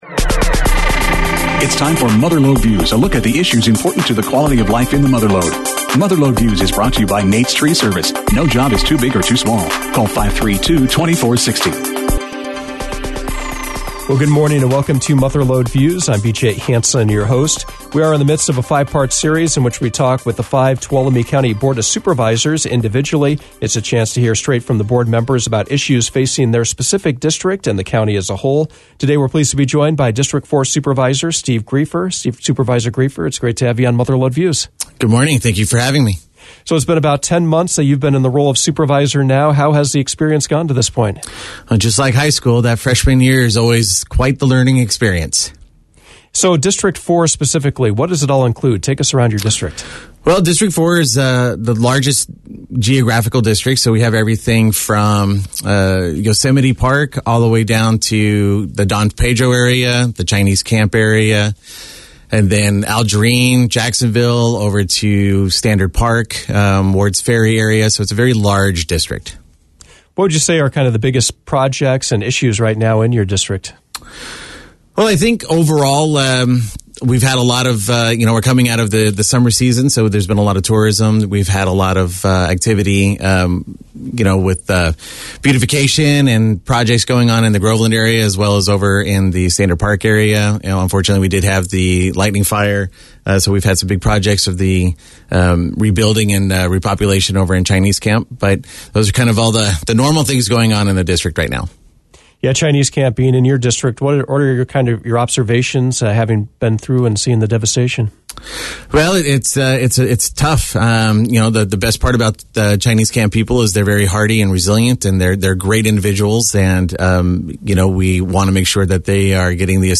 Mother Lode Views featured a conversation with District Four Tuolumne County Supervisor, Steve Griefer. It is the second part in our series featuring the five individual board members speaking about issues facing both their district and the county as a whole.